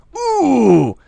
mp_oooooh.wav